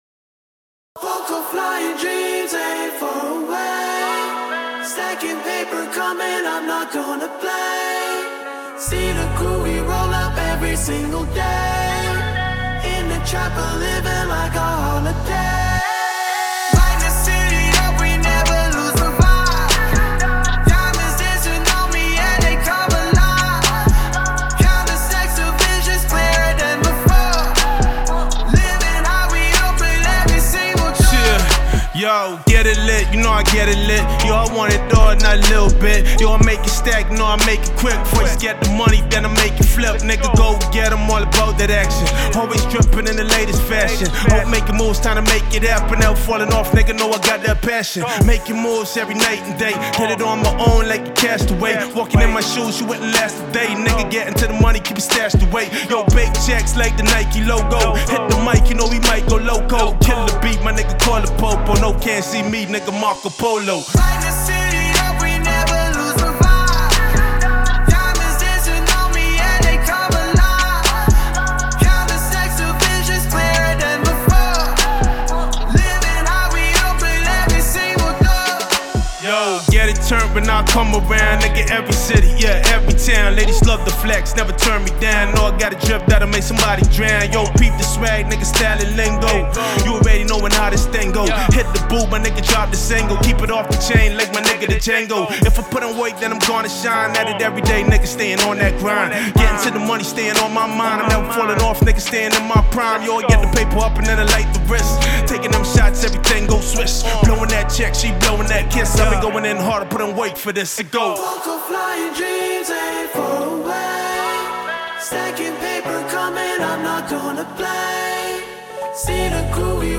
This is a mp3 acapella file and does not include stems